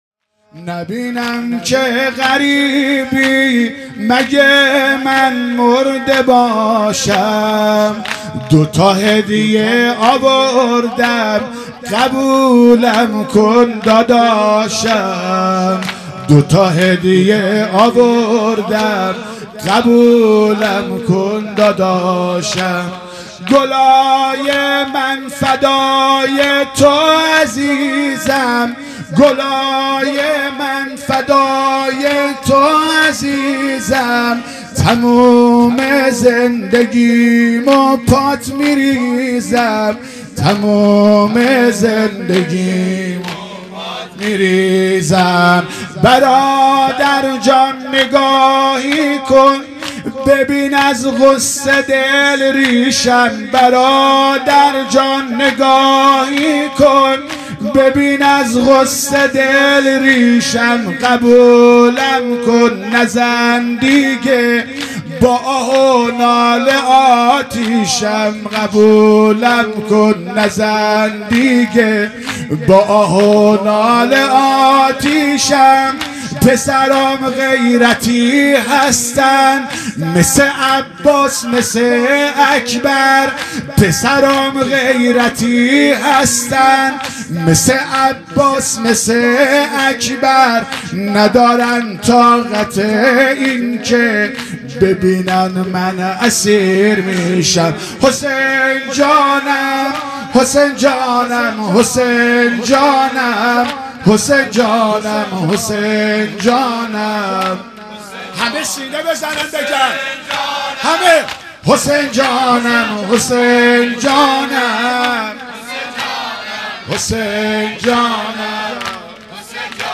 محرم الحرام ۱۴۴۱ ، ۱۳۹۸ شب چهارم